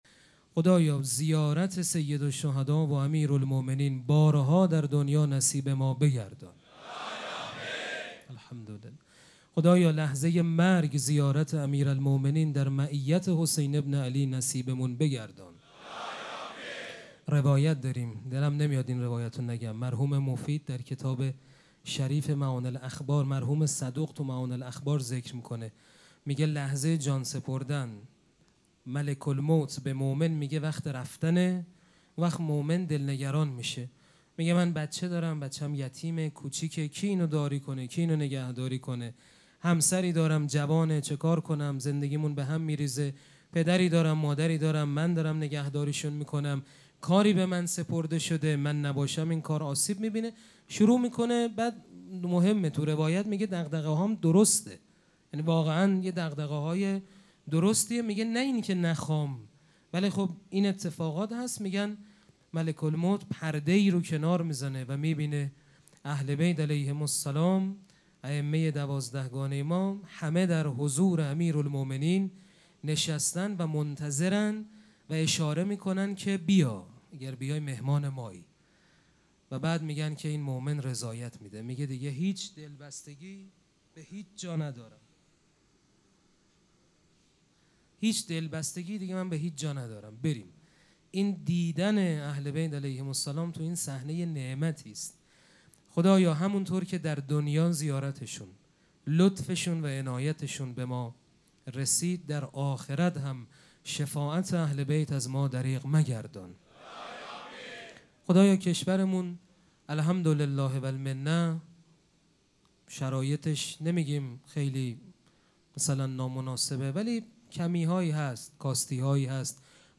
سخنرانی
مراسم عزاداری شب نهم محرم الحرام ۱۴۴۷ جمعه ۱۳ تیر۱۴۰۴ | ۸ محرم‌الحرام ۱۴۴۷ هیئت ریحانه الحسین سلام الله علیها